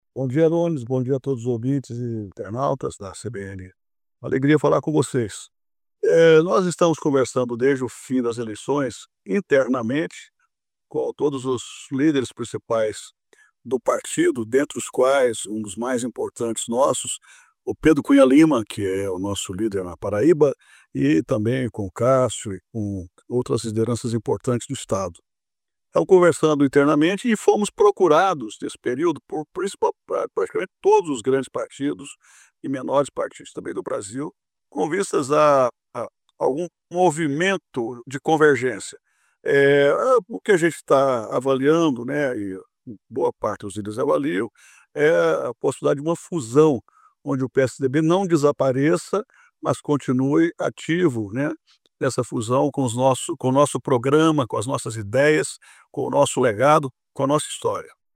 O presidente nacional do PSDB, Marconi Perillo, confirmou nesta quarta-feira (12), em declaração exclusiva à Rádio CBN Paraíba, conversas com lideranças de outras legendas, visando a sobrevivência dos tucanos a partir de uma fusão partidária.